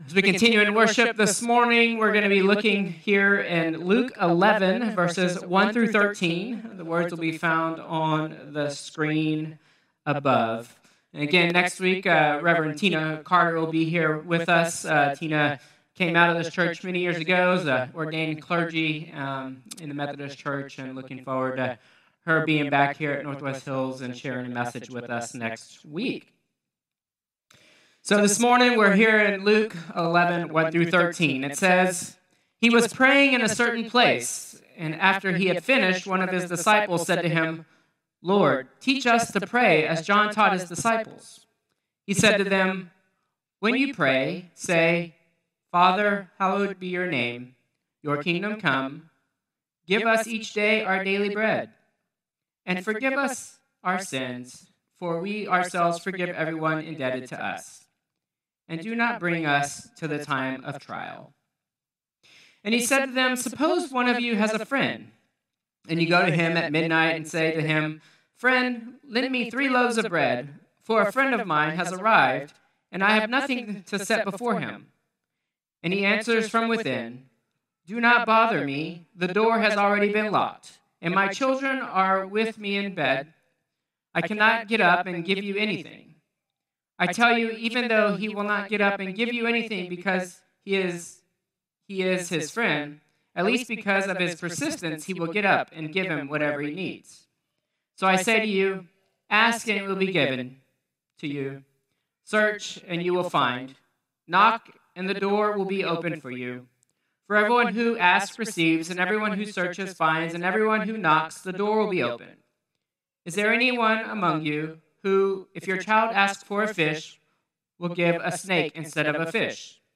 Contemporary Service 7/27/2025